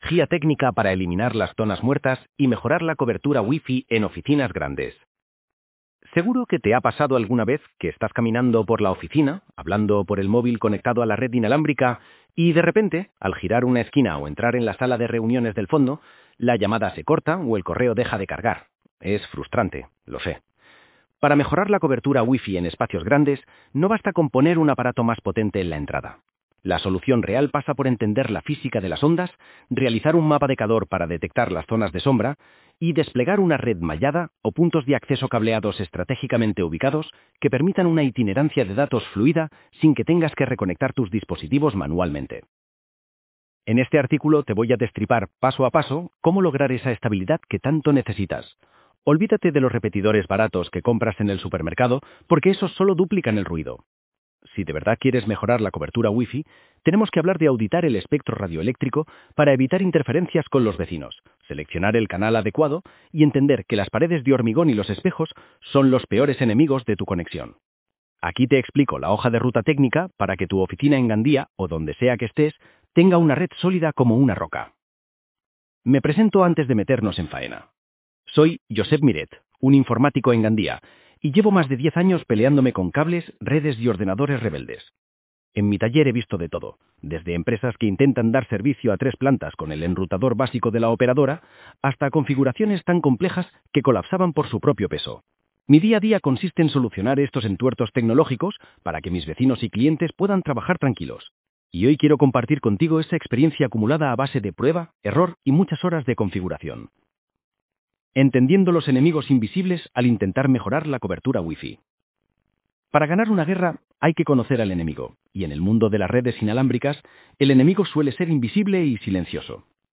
Dale al play para escuchar el artículo Mejorar la cobertura WiFi